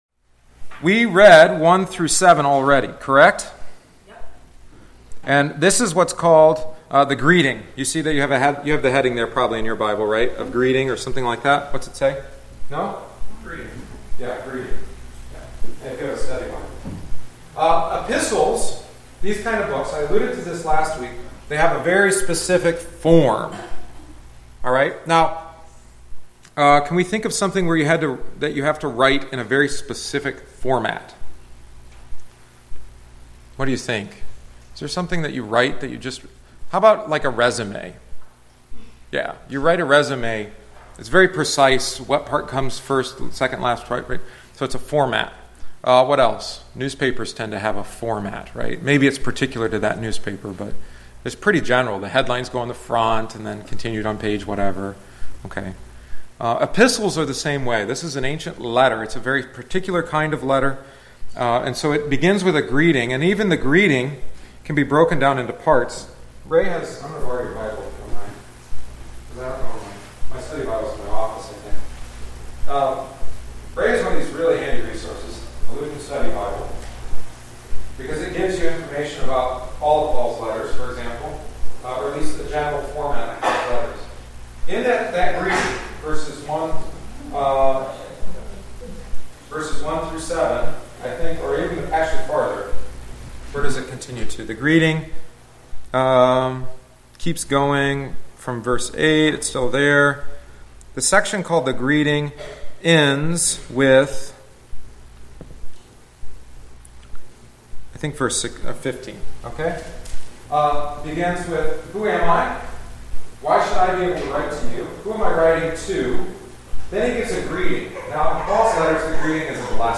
If you were unable to join us for our continued study of St. Paul’s letter to the church in Rome, attached is the second week’s lesson.